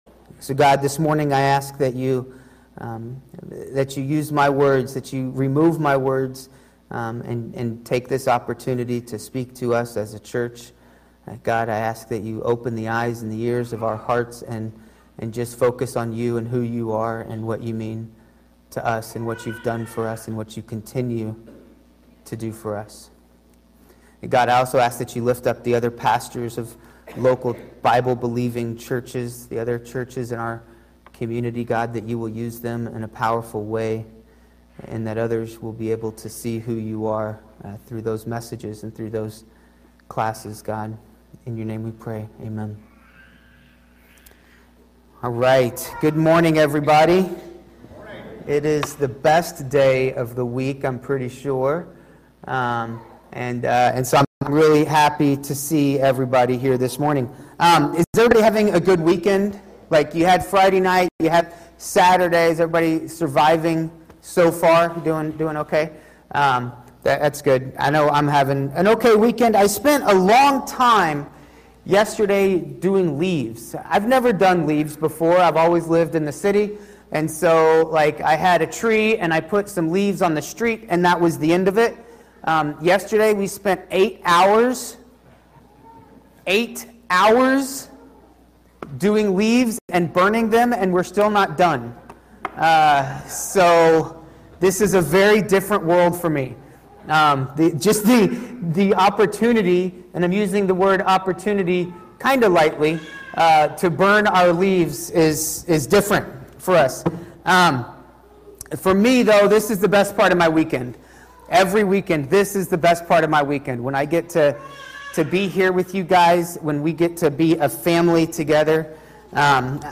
Messages | Leaf River Baptist Church